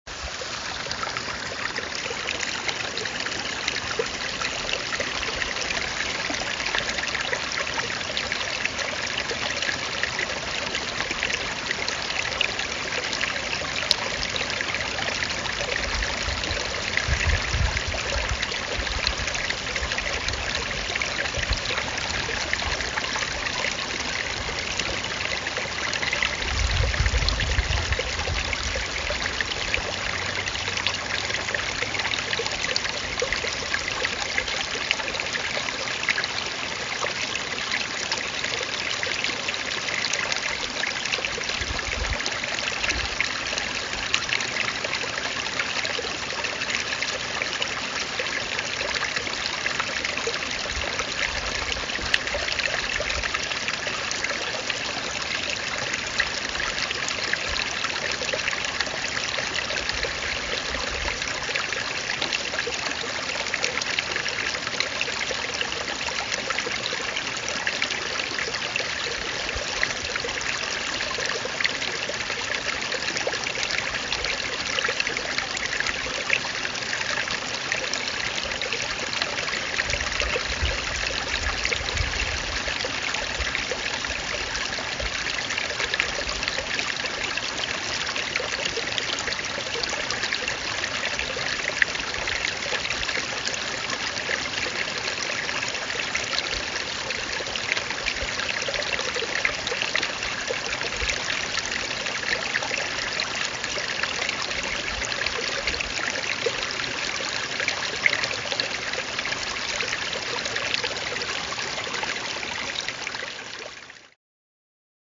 A rainy morning in Glenmore Forest in the Cairngorms national park in Scotland, on the walk towards Meall a' Bhuachaille.
Walking in one of the highest parts of the Cairngorms, but it was very hard to see due to bad weather and dense grey cloud, following a natural rocky path and recording one of the many streams, with absolutely no other people around.